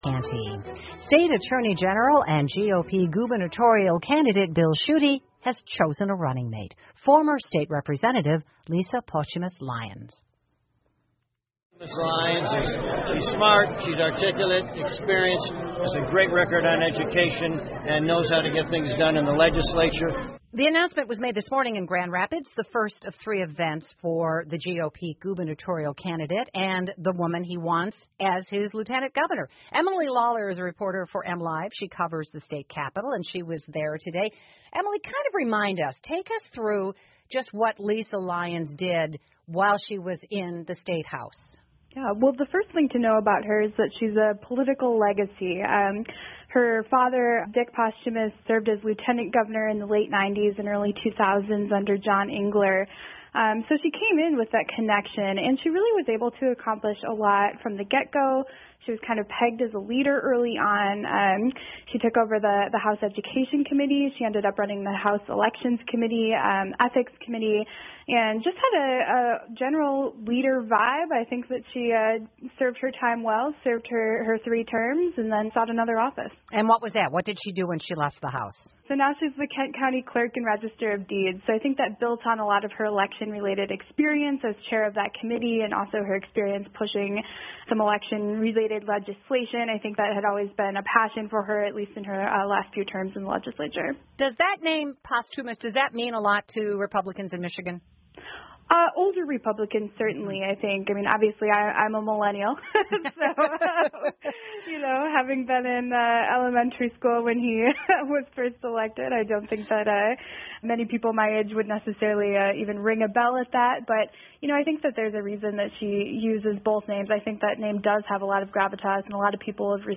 密歇根新闻广播 比尔·舒特选择莱恩斯作为竞选搭档 听力文件下载—在线英语听力室